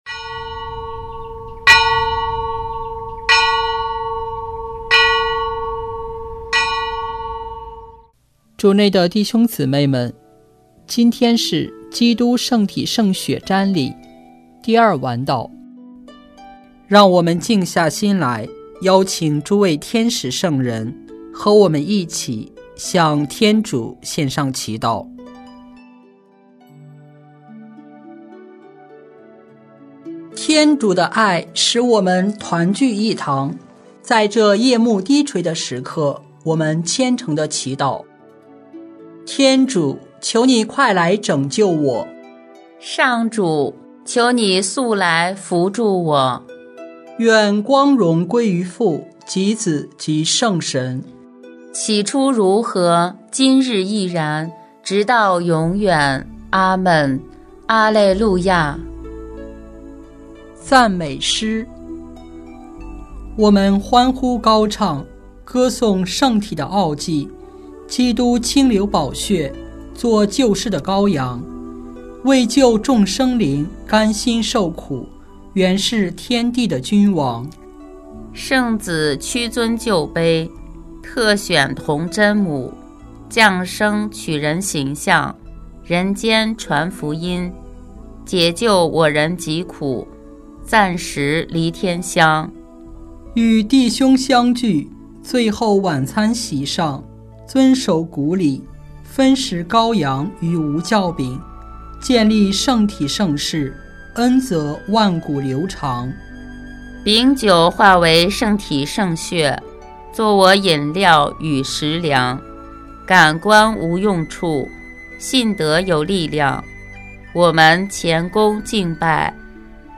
【每日礼赞】|6月19日基督圣体圣血主日第二晚祷